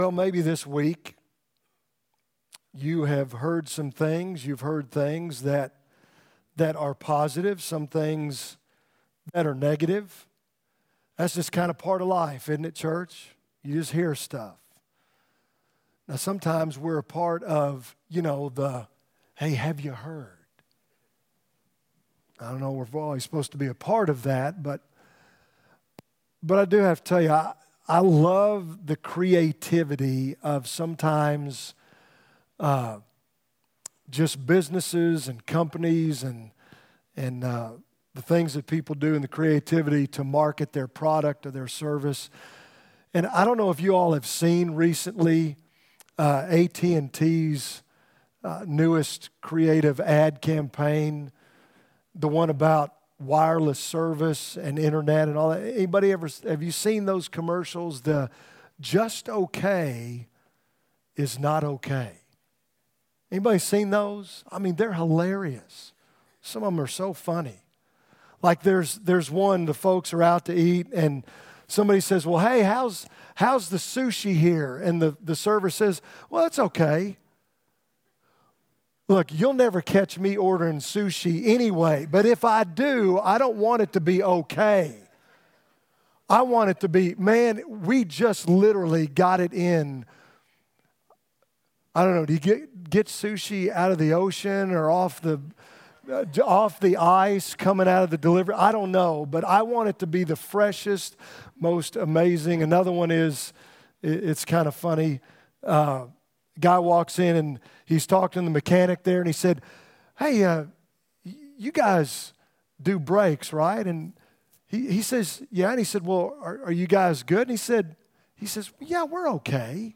From Series: "2019 Sermons"